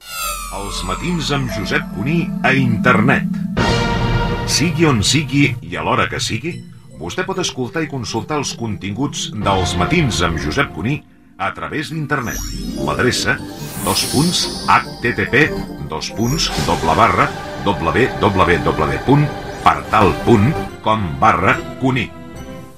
Promoció del programa a Internet.